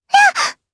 Lavril-Vox_Damage_jp_02.wav